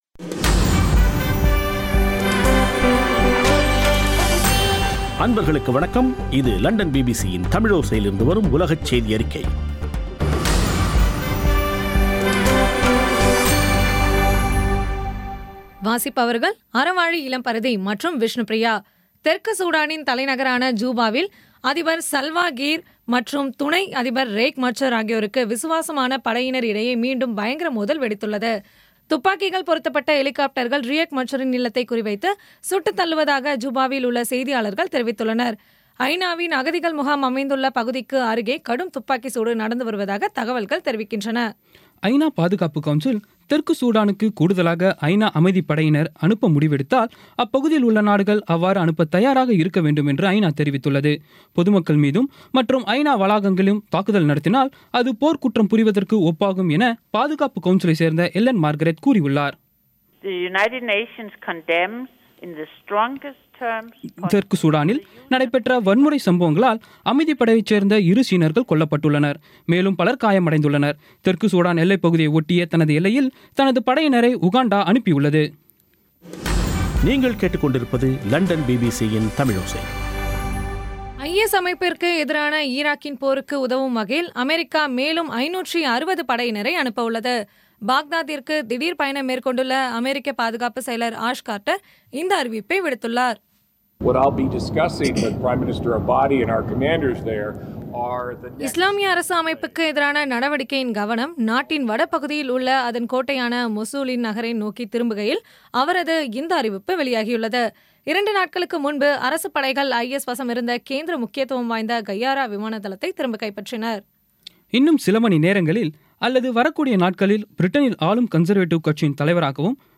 இன்றைய (ஜூலை 11ம் தேதி ) பிபிசி தமிழோசை செய்தியறிக்கை